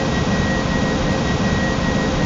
v2500-whine.wav